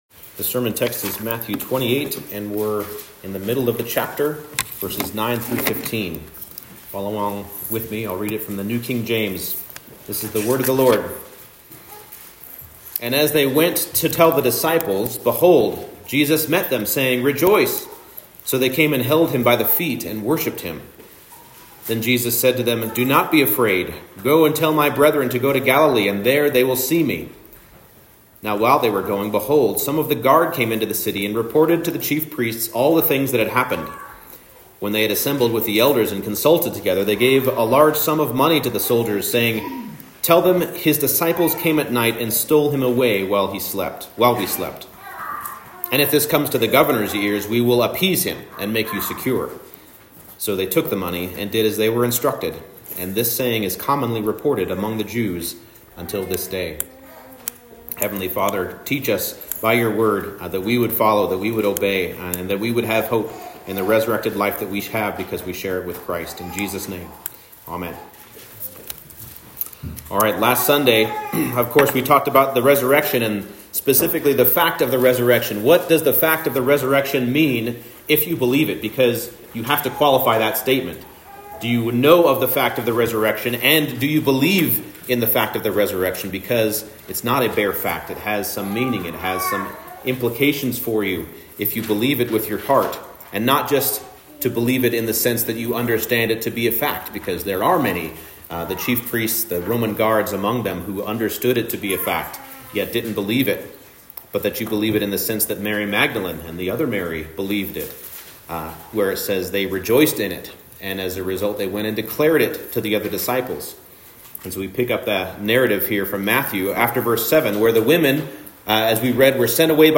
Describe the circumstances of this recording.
Matthew 28:9-15 Service Type: Morning Service The attempted cover-up of the Resurrection serves to establish it as a fact.